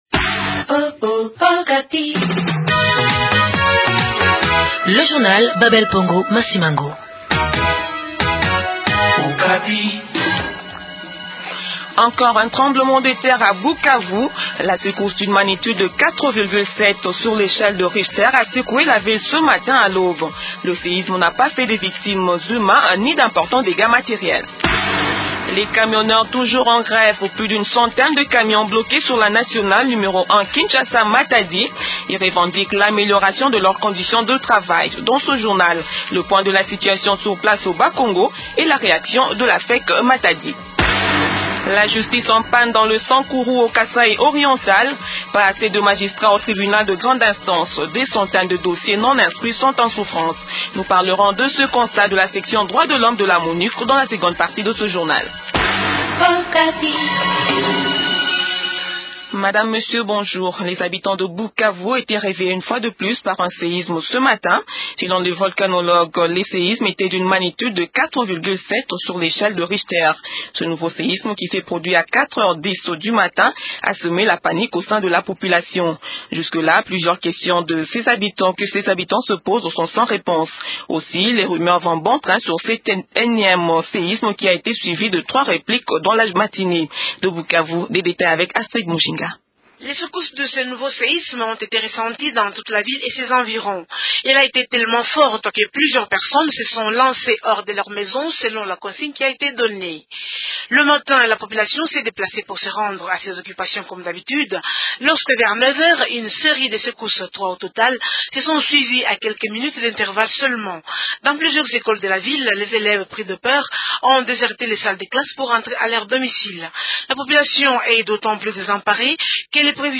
Journal Français Midi